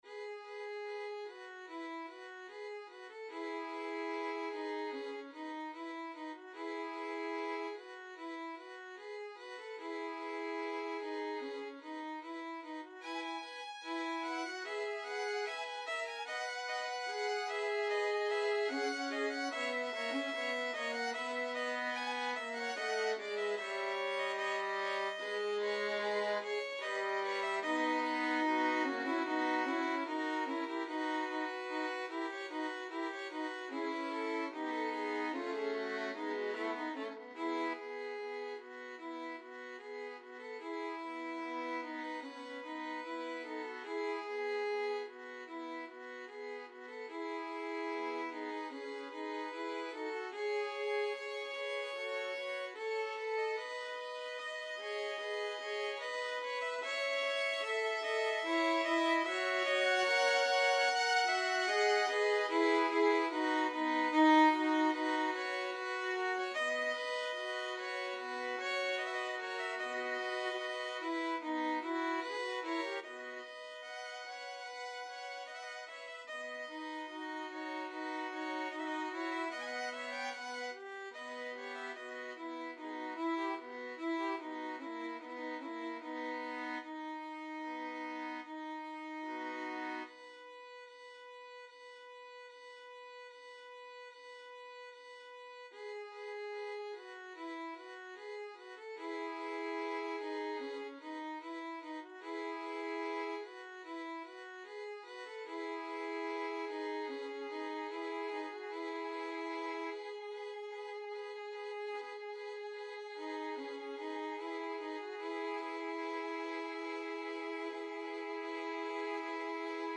Free Sheet music for Violin Trio
Violin 1Violin 2Violin 3
4/4 (View more 4/4 Music)
~ = 74 Moderato
E major (Sounding Pitch) (View more E major Music for Violin Trio )
dvorak_serenade_op22_1st_3VLN.mp3